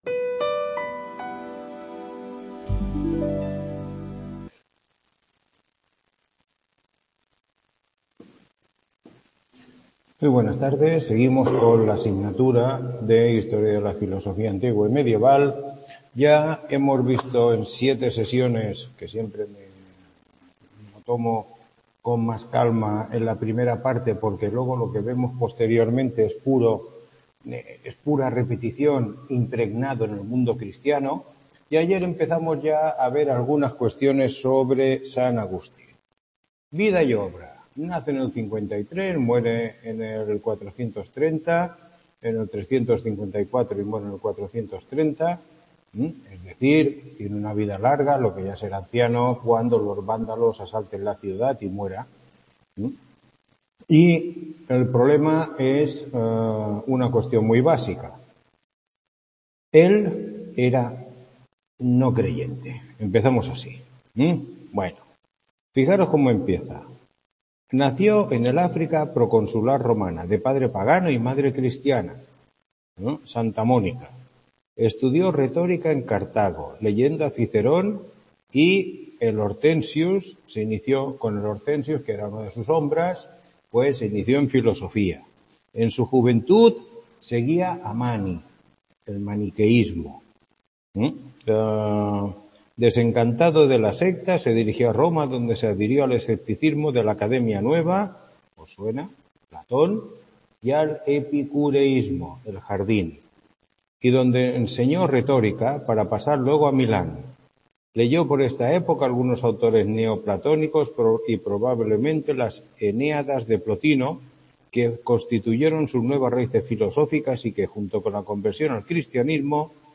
Tutoría 08